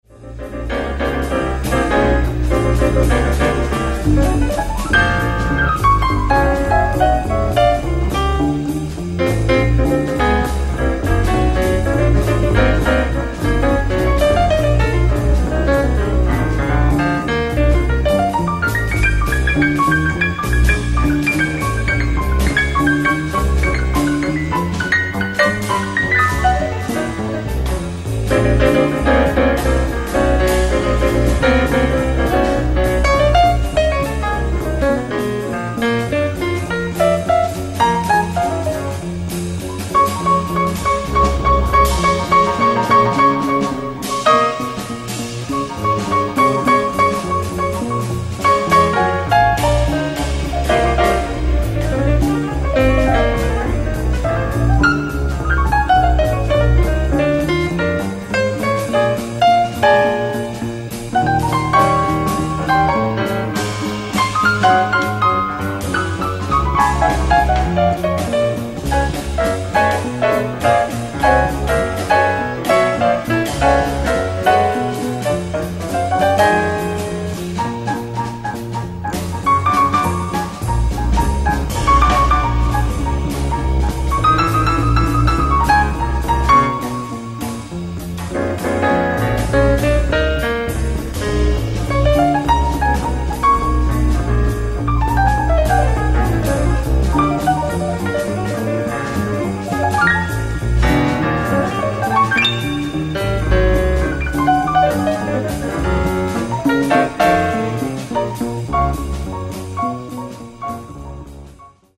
ライブ・アット・クィーン・エリザベスホール、ロンドン 11/13/1991
※試聴用に実際より音質を落としています。